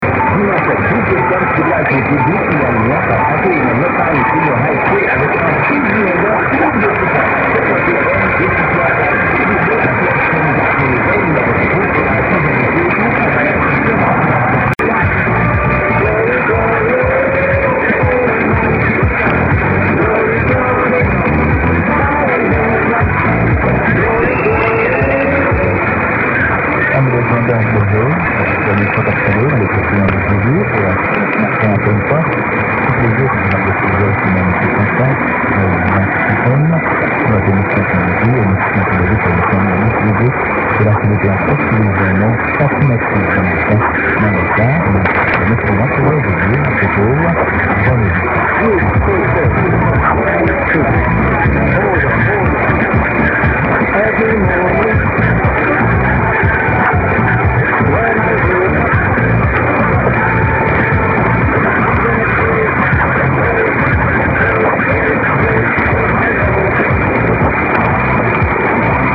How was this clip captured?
100825_0501_1270_unid_mx_and_ss_long_noisy_clip.mp3